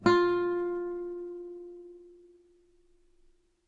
Notes on nylon strings » Low D
描述：Low D, on a nylon strung guitar. belongs to samplepack "Notes on nylon guitar".
标签： guitar lowd music note nylon string strings
声道立体声